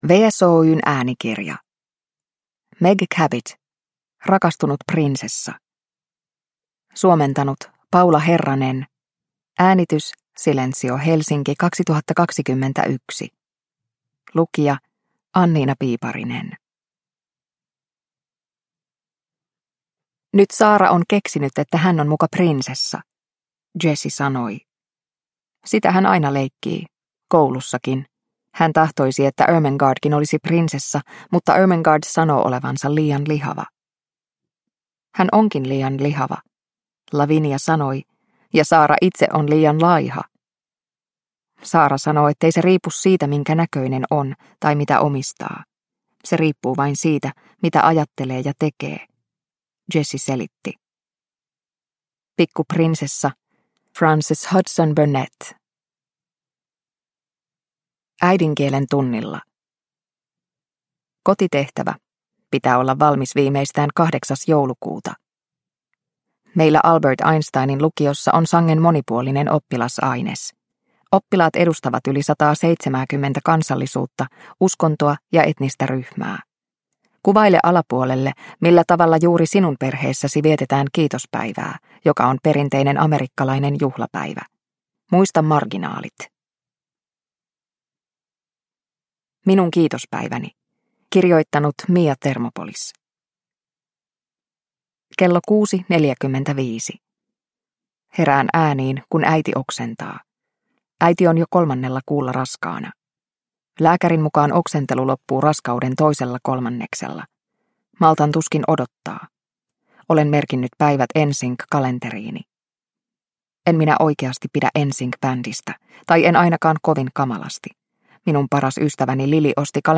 Rakastunut prinsessa – Ljudbok – Laddas ner